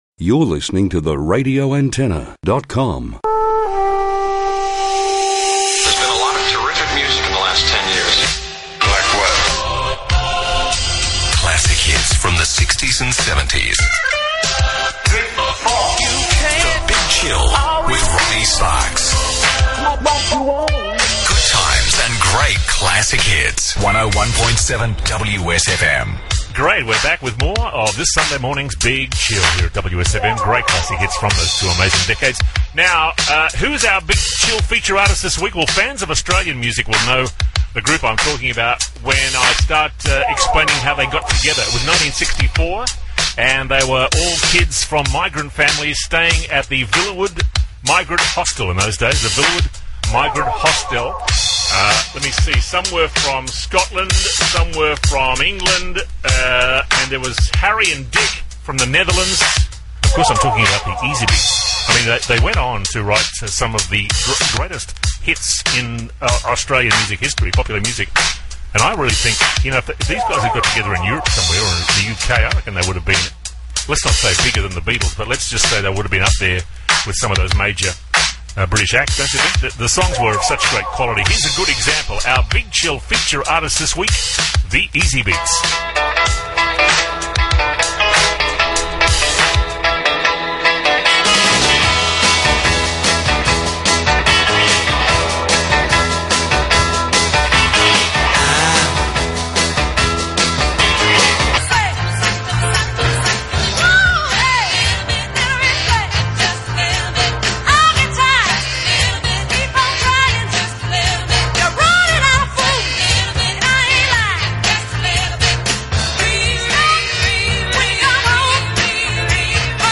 Here’s a snapshot of 15 mins after the 7am News, scoped